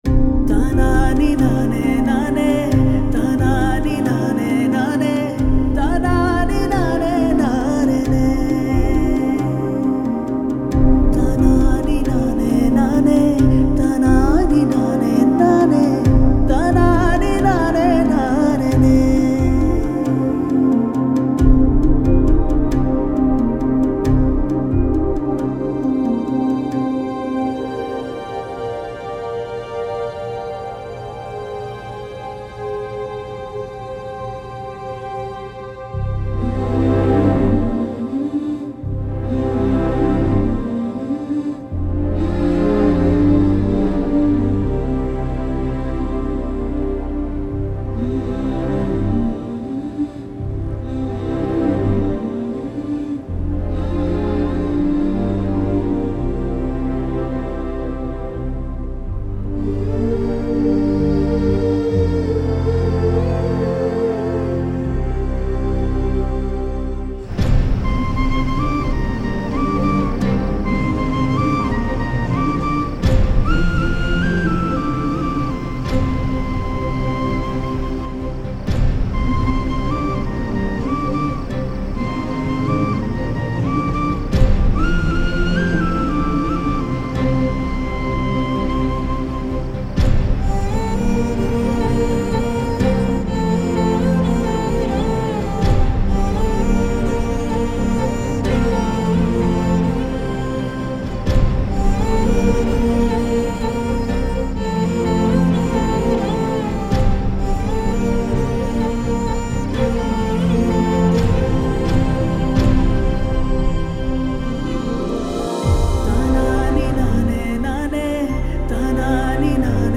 without any dialogues and unwanted sounds